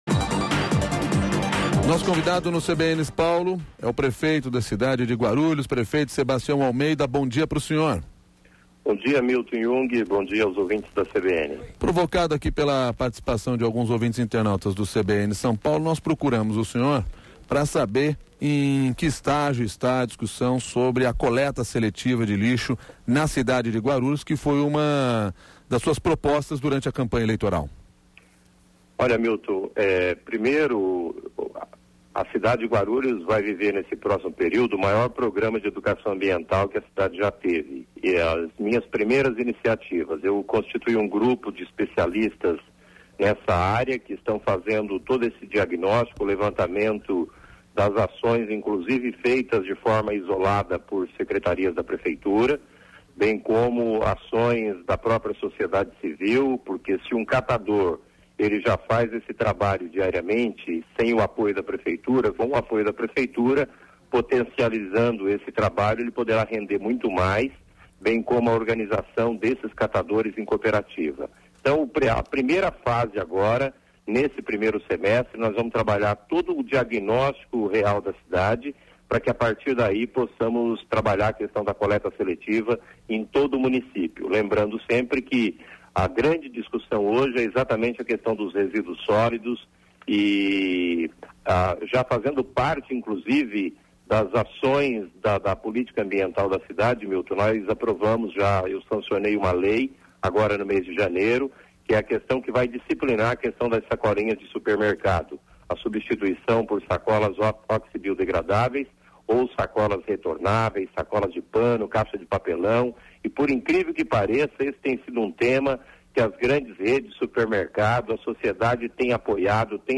Ouça a entrevista do prefeito Sebastião Almeida, de Guarulhos, na qual fala sobre outras medidas na área do meio ambiente